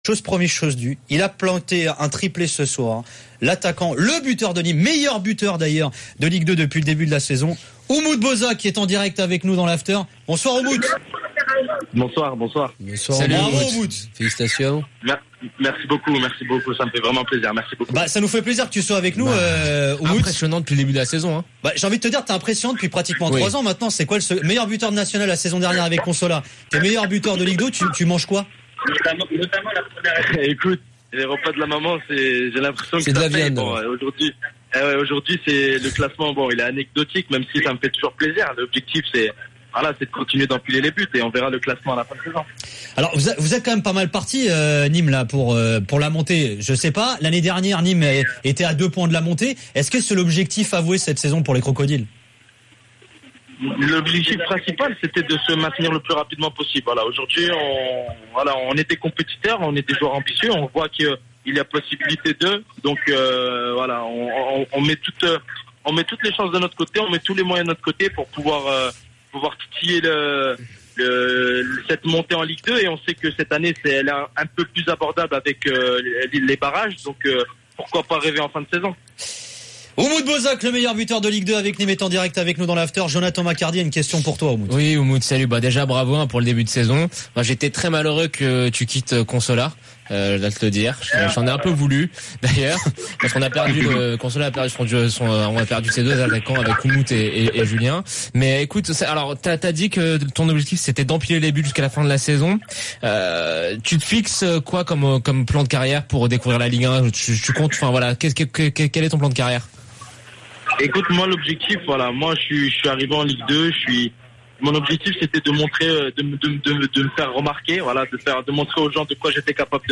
RMC : 24/11 - Le Top de l'Afterfoot : L'interview d'Umut Bozok